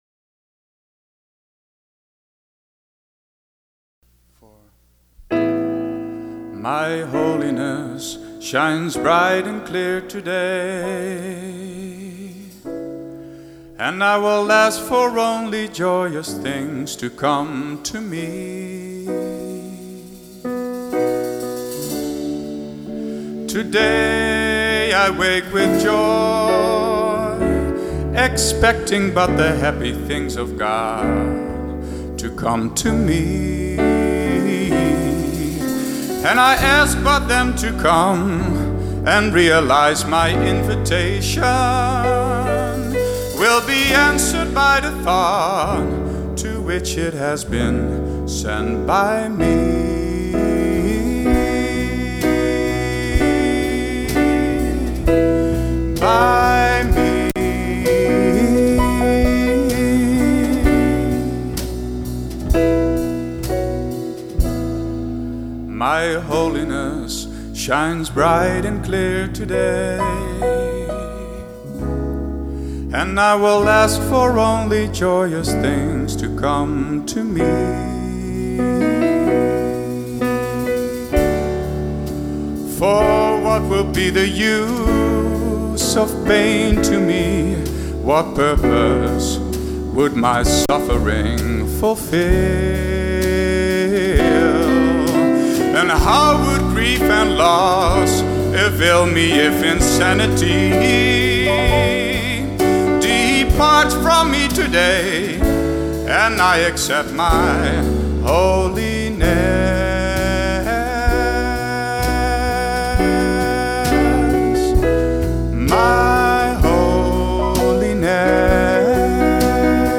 Download Muziek - My Holiness Shines Bright And Clear Today (Live)